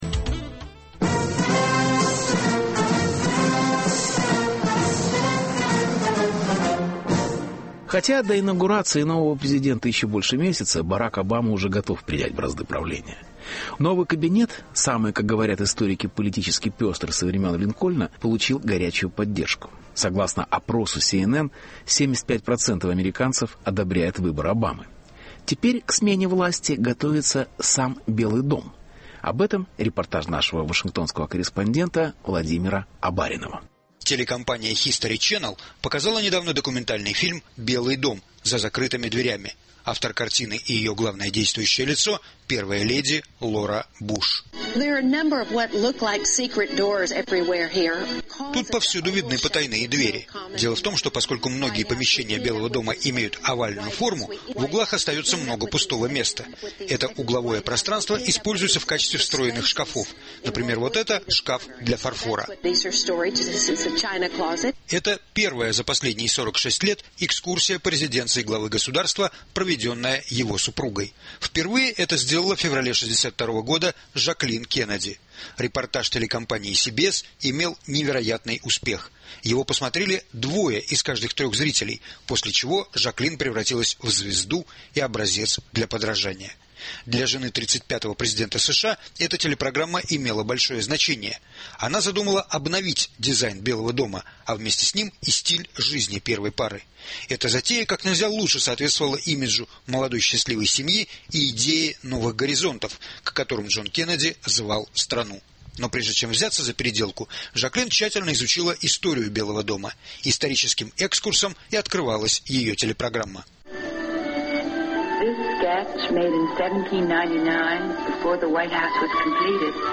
Белый дом готовится к переменам -репортаж